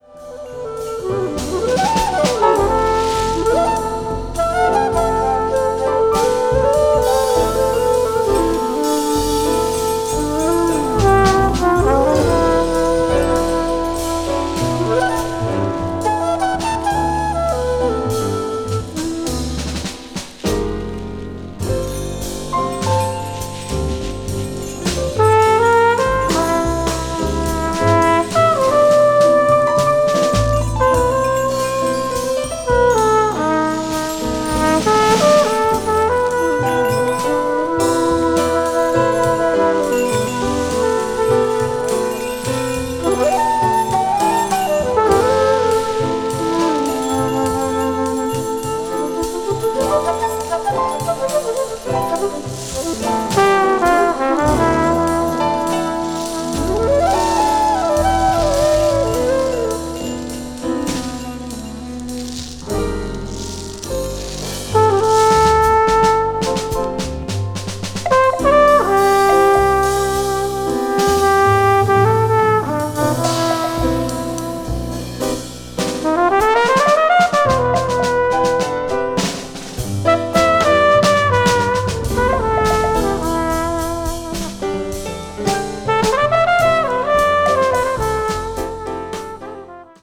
earthy flute soars organically